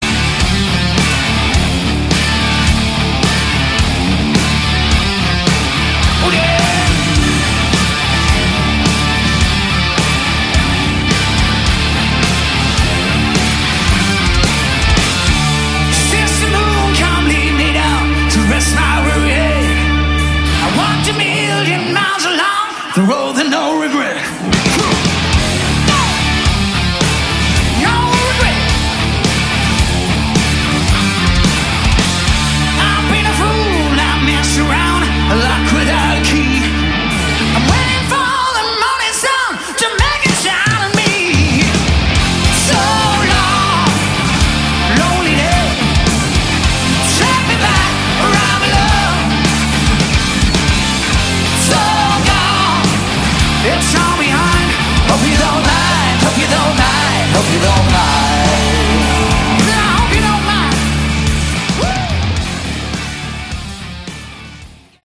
Category: Melodic Hard Rock